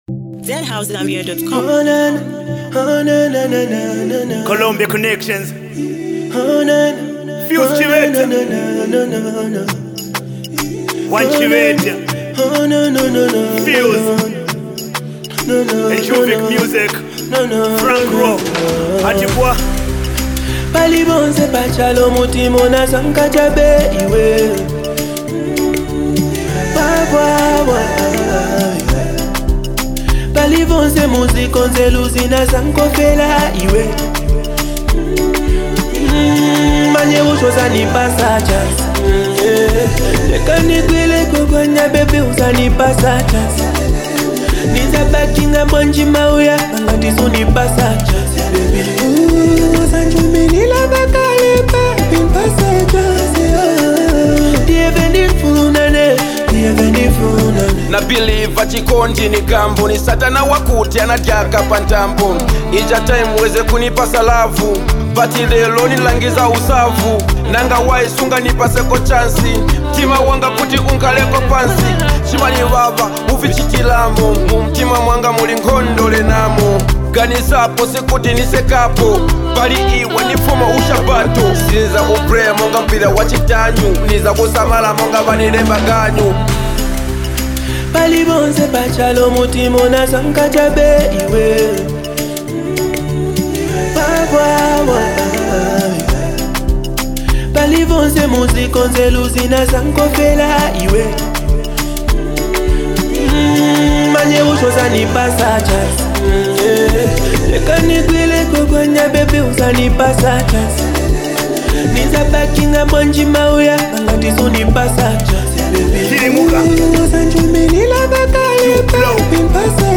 Catchy vibes, smooth flow, and pure Zambian energy!